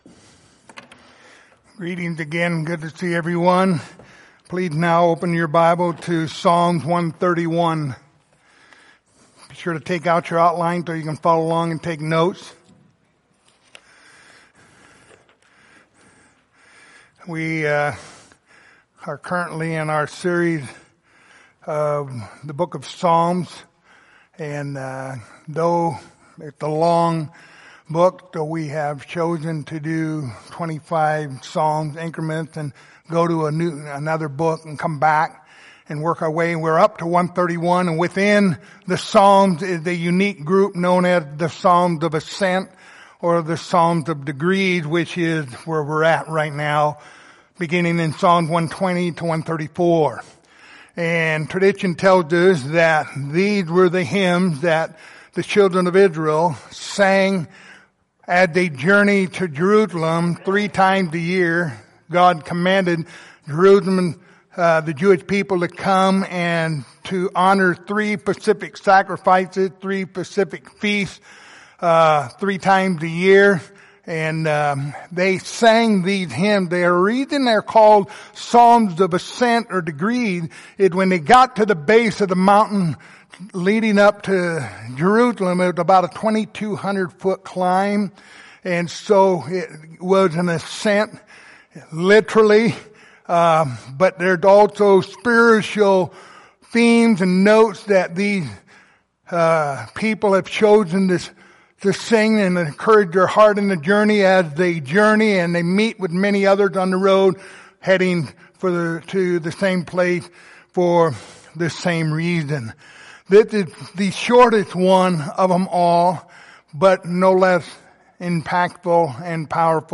Passage: Psalm 131:1-3 Service Type: Sunday Morning